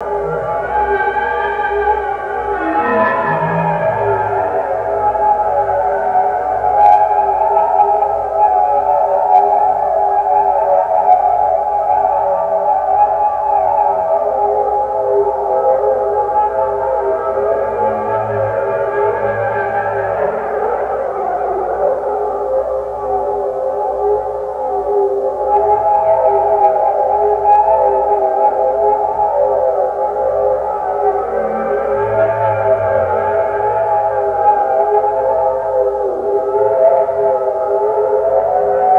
Index of /90_sSampleCDs/E-MU Producer Series Vol. 3 – Hollywood Sound Effects/Science Fiction/Photon Transport